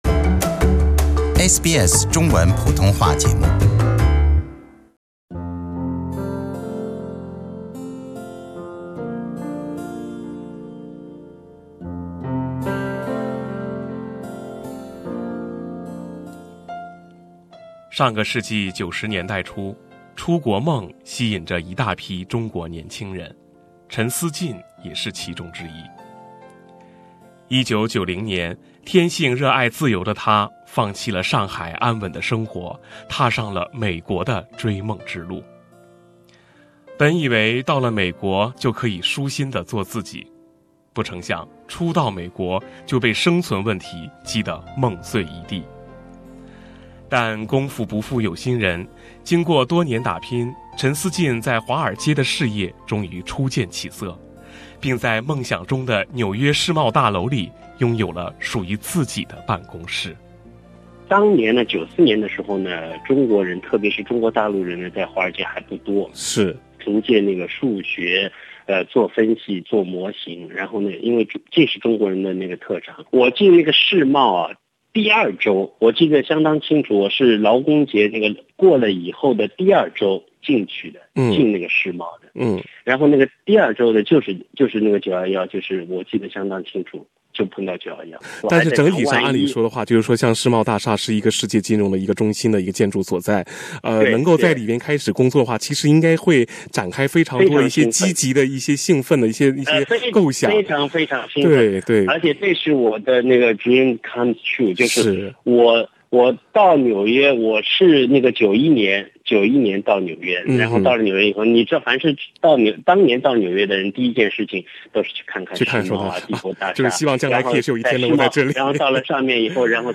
与死神擦肩而过的劫后重生 - 911恐袭幸存者口述